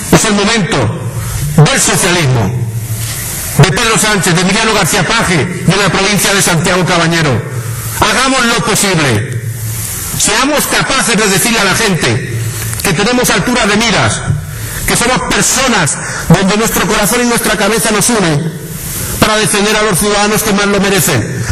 El Palacio de Congresos de Albacete ha acogido esta mañana el acto de inauguración del 15 Congreso Provincial del PSOE.
Cortes de audio de la rueda de prensa